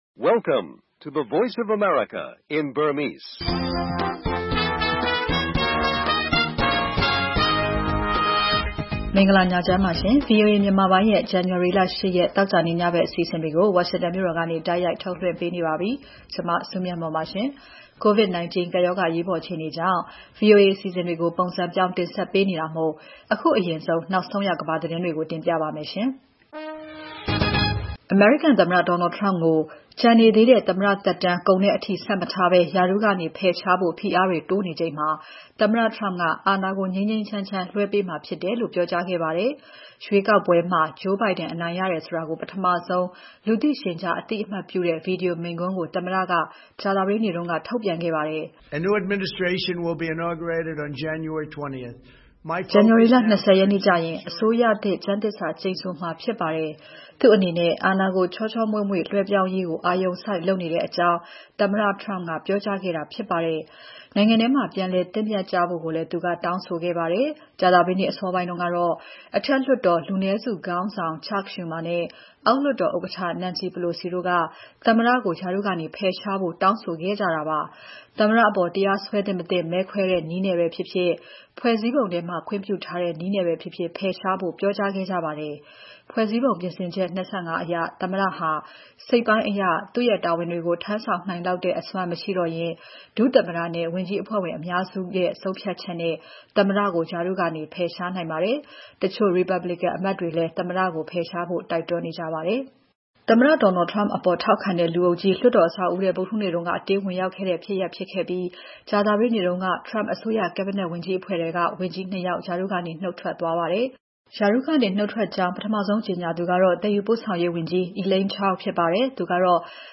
အမျိုးသမီးကဏ္ဍ၊ သုတစုံလင် အမေရိကန်တခွင်၊ သိပ္ပံနဲ့နည်းပညာ အပတ်စဉ်ကဏ္ဍတွေနဲ့အတူ ည ၉း၀၀ - ၁၀း၀၀ ရေဒီယိုအစီအစဉ်